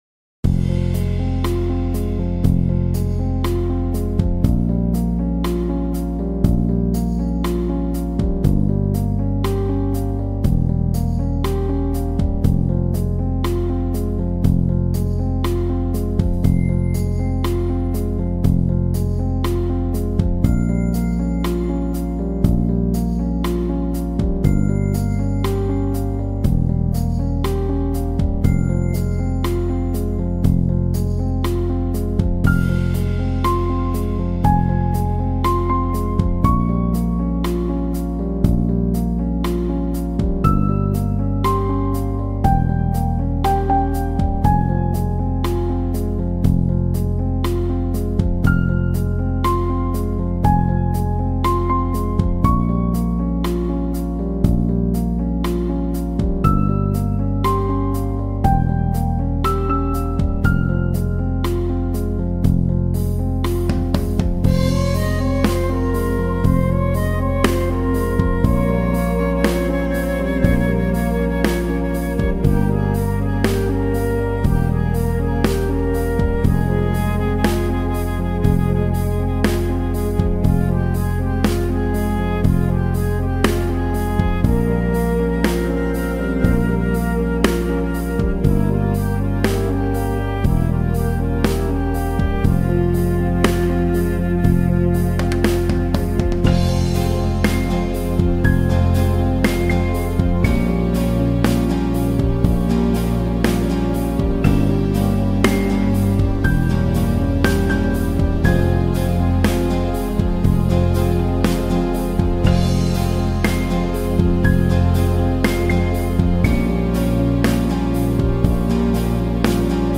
از این آهنگ بی کلام و پاییزی لذت ببرید